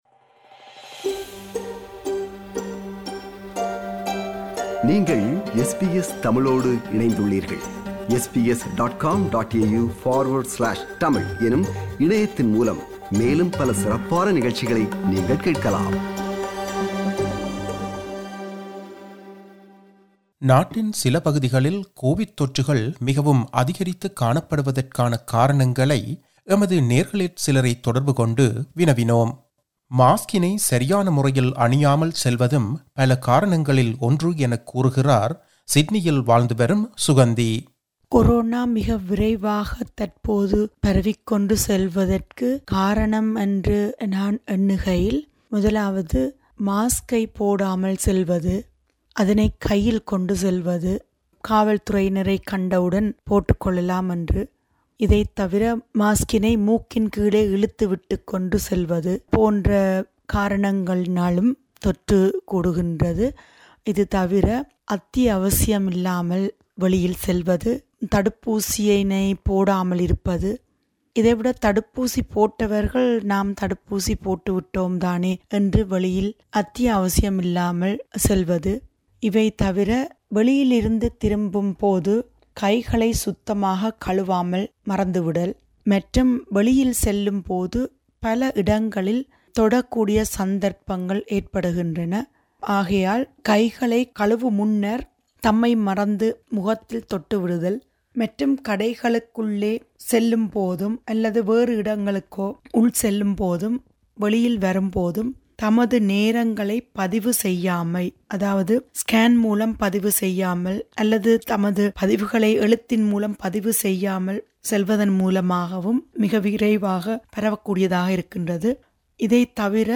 Australia, once a world leader in curtailing COVID-19, is struggling to suppress a third wave of infections driven by the highly infectious Delta variant despite locking down more than half its population. Few listeners from Sydney, Melbourne and Canberra share their views about the spread.